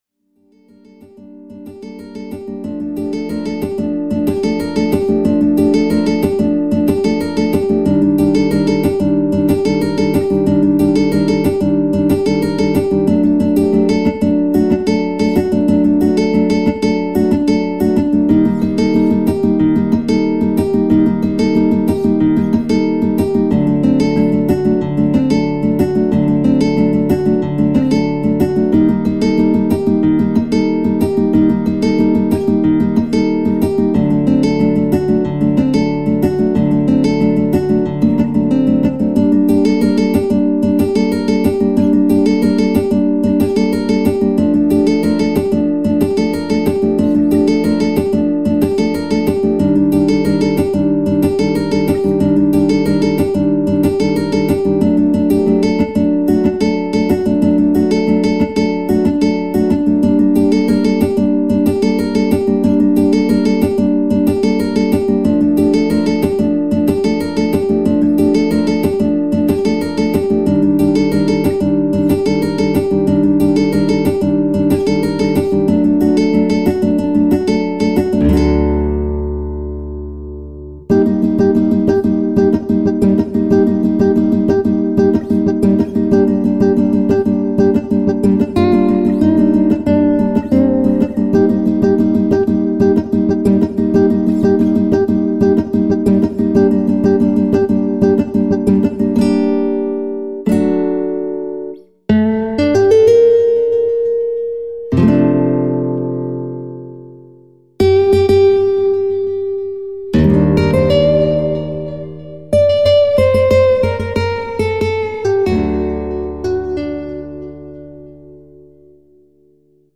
HALion6 : A.Guitar
Studio Nylon Guitar